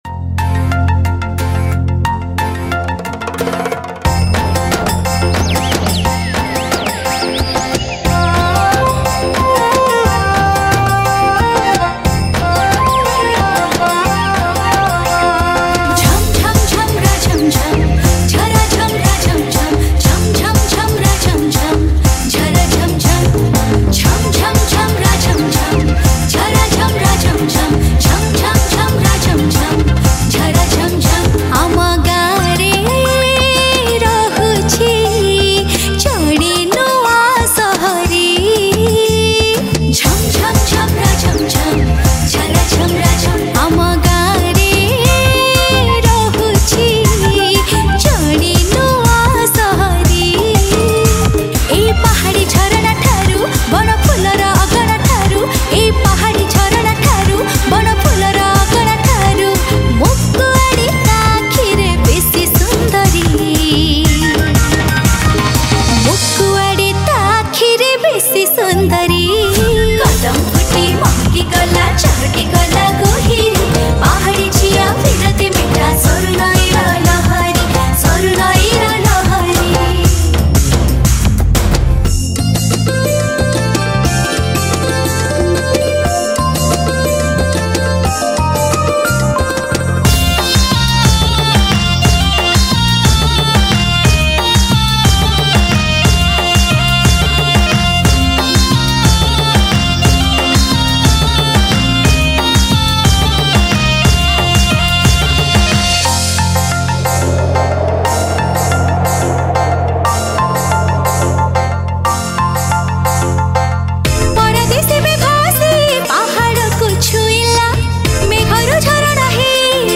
Romantic Odia Song